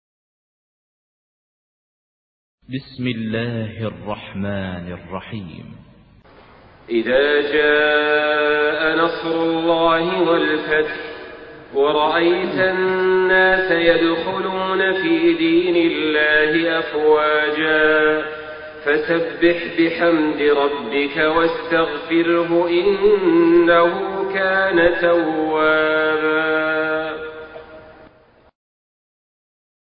Surah An-Nasr MP3 by Saleh Al-Talib in Hafs An Asim narration.
Murattal